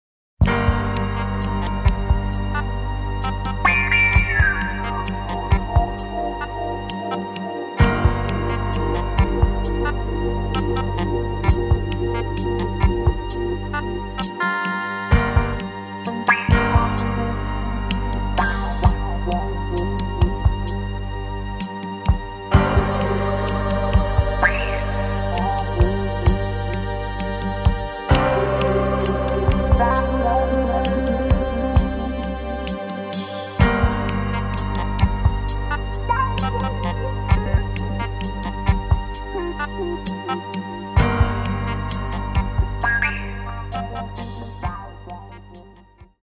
in the idioms of funk, jazz and R&B